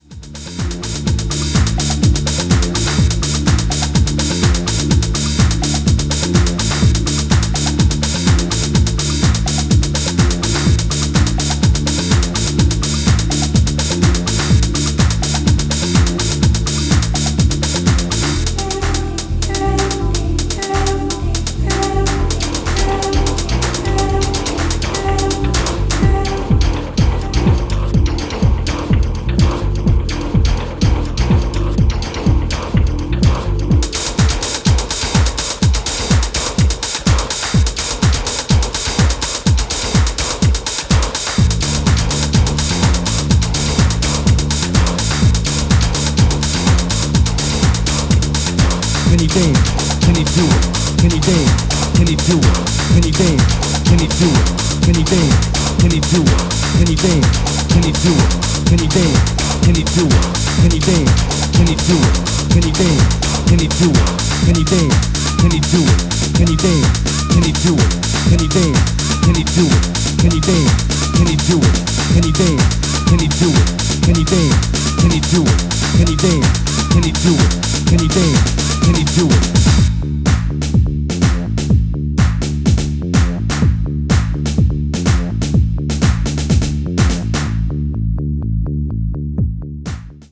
techno cuts from the early nineties
underground dance music